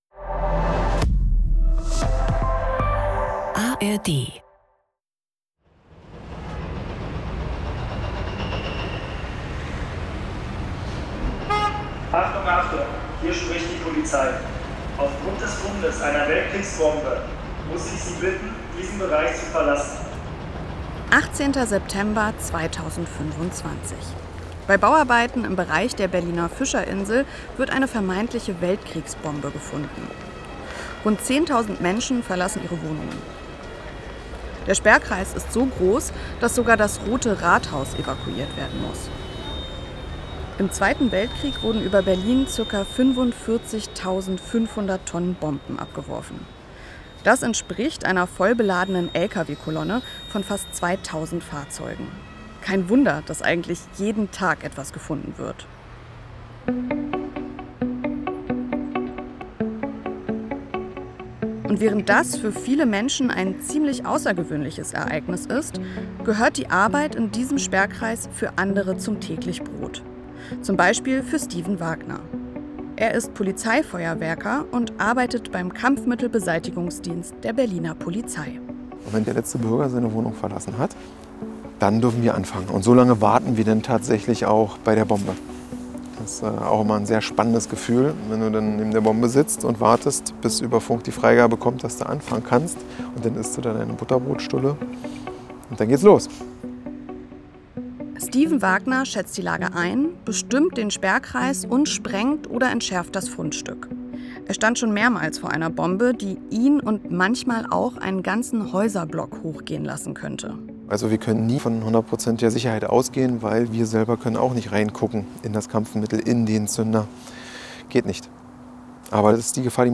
Sie fahren zum Sprengplatz im Grunewald, holen gemeinsam geborgene Kampfmittel von einer Baustelle und sprechen über die Gefahren und Herausforderungen dieses Berufes. Es wird deutlich: Hier wird nicht auf Heldentum gesetzt, sondern auf Vorbereitung, Teamarbeit und Respekt.